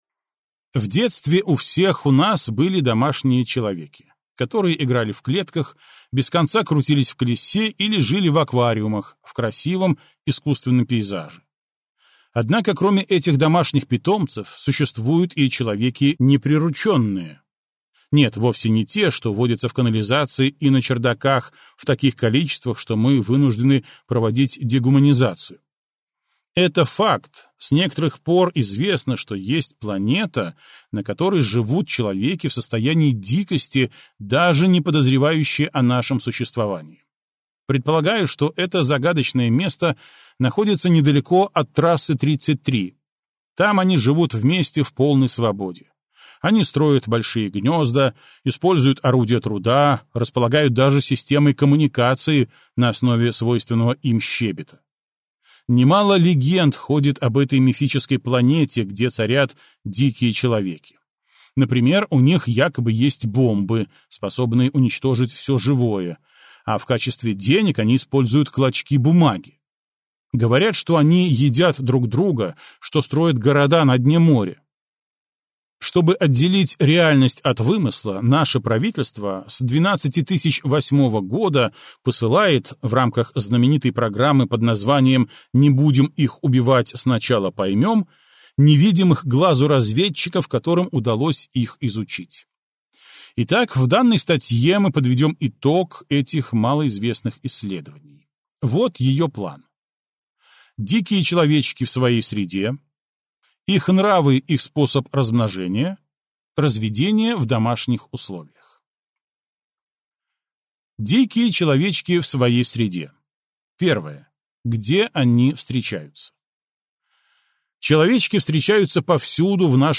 Аудиокнига Древо возможностей (сборник) - купить, скачать и слушать онлайн | КнигоПоиск